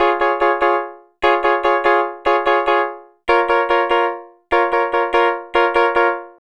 Twisting 2Nite 3 Piano-C#.wav